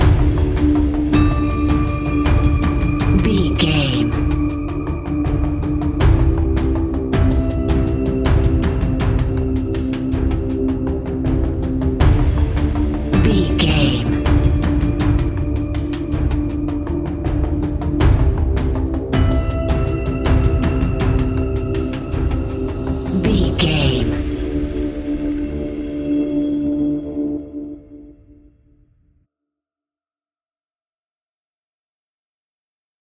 In-crescendo
Aeolian/Minor
scary
ominous
dark
haunting
eerie
ticking
electronic music
Horror Pads
Horror Synths